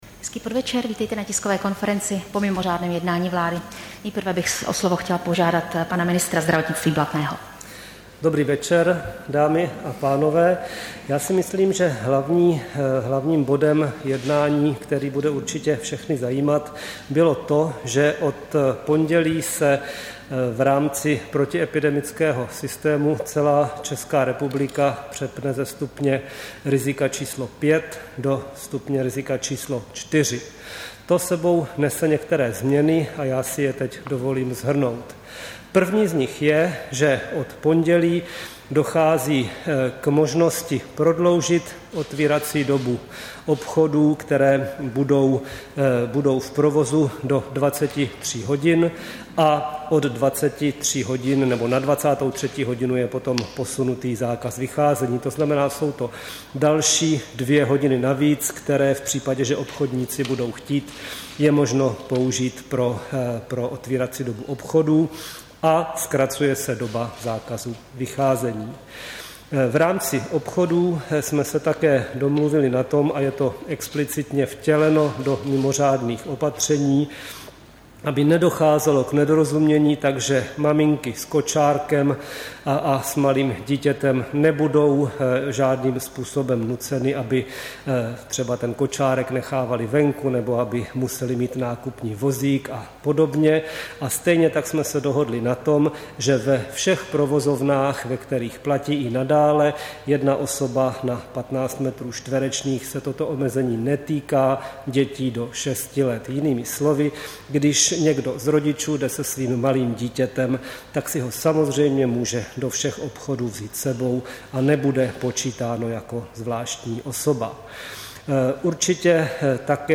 Tisková konference po mimořádném jednání vlády, 20. listopadu 2020